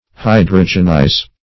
hydrogenize.mp3